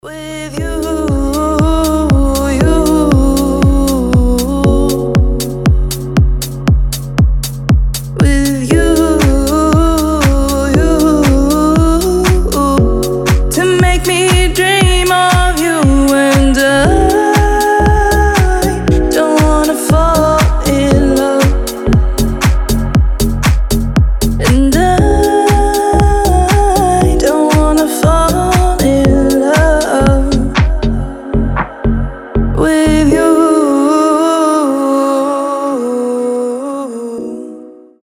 • Качество: 320, Stereo
женский вокал
deep house
чувственные
Кавер-ремикс известной песни